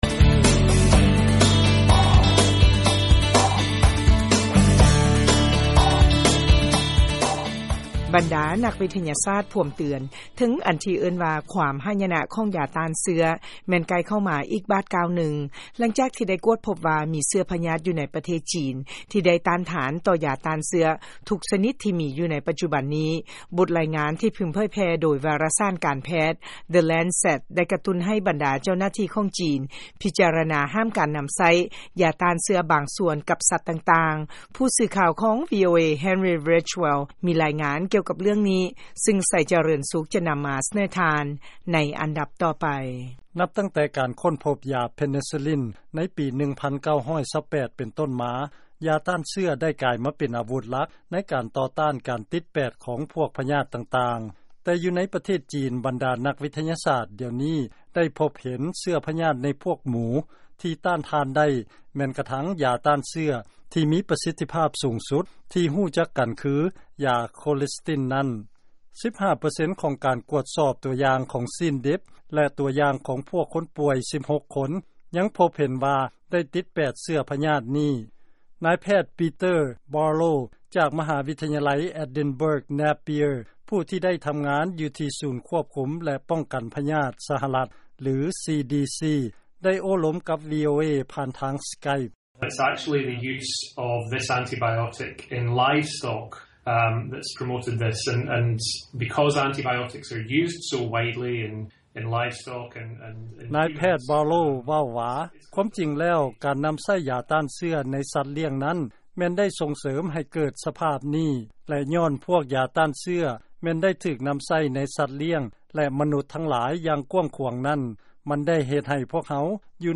ຟັງລາຍງານ ບັນດານັກວິທະຍາສາດ ເຕືອນວ່າ ຄວາມຫາຍຍະນະ ຂອງຢາຕ້ານເຊື້ອ ແມ່ນໃກ້ເຂົ້າມາແລ້ວ.